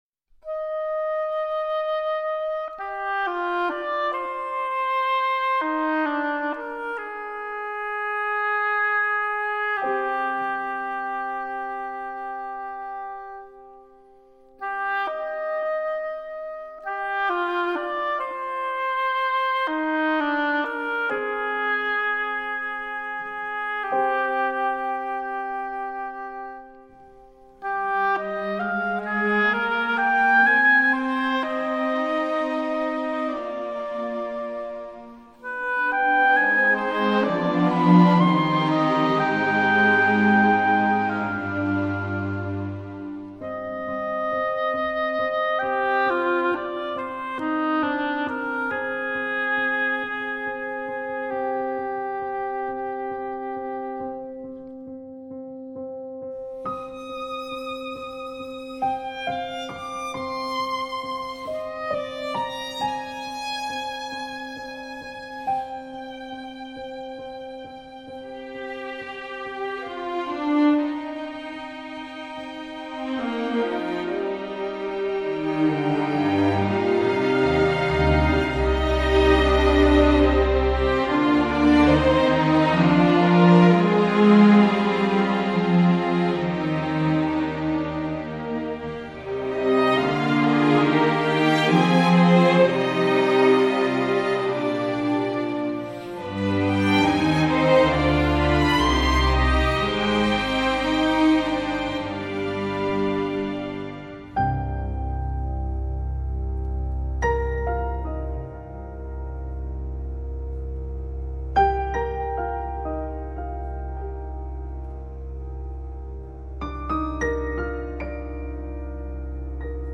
φωνητικό σύνολο
μπάντα χάλκινων πνευστών